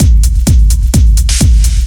• techno big kick and open ride loop.wav
techno_big_kick_and_open_ride_loop_F1g.wav